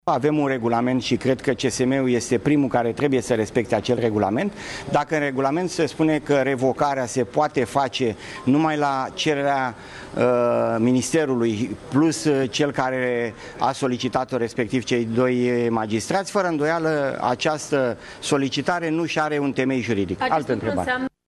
Ministrul Justiției, Florin Iordache, prezent în această dimineață la sediul CSM, a criticat decizia Consiliului: